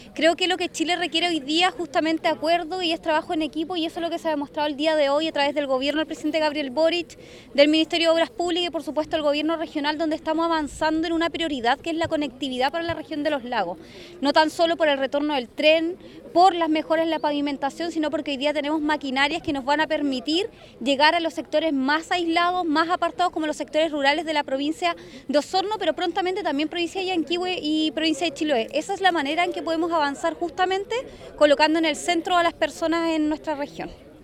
La Delegada Presidencial Regional, Giovanna Moreira indicó que estas acciones dejan de manifiesto el compromiso gubernamental con todos los sectores del territorio.